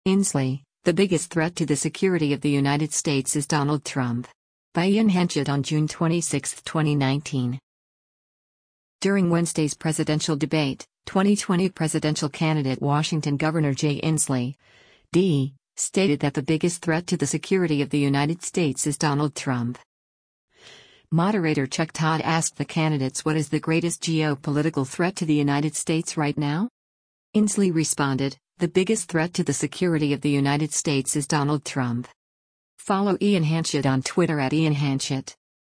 During Wednesday’s presidential debate, 2020 presidential candidate Washington Governor Jay Inslee (D) stated that “The biggest threat to the security of the United States is Donald Trump.”
Moderator Chuck Todd asked the candidates what is the “greatest geopolitical threat to the United States right now?”